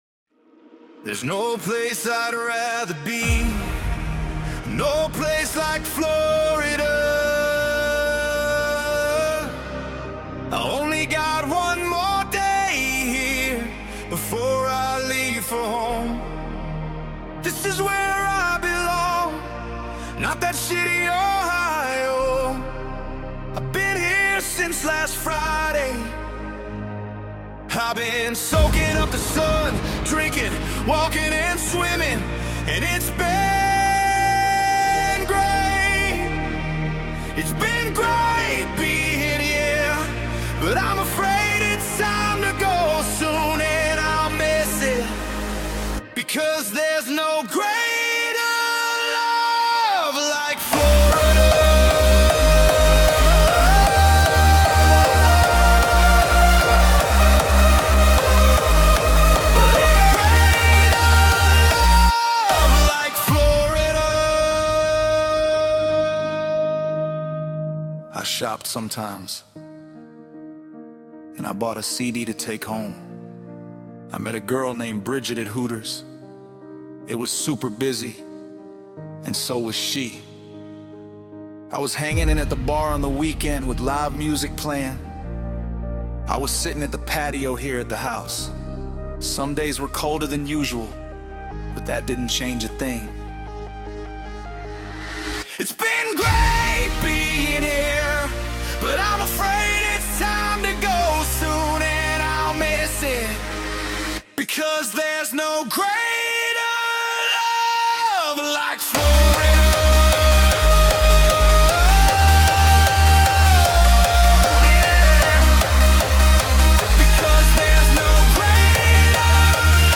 Think of it as an emotional hardstyle track.
I had to screen record this because you can't download songs with Suno if you're using a free account because of the ordeal with Warner music group. Then I converted it to audio.